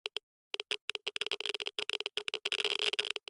Minecraft Version Minecraft Version latest Latest Release | Latest Snapshot latest / assets / minecraft / sounds / ambient / nether / basalt_deltas / click6.ogg Compare With Compare With Latest Release | Latest Snapshot
click6.ogg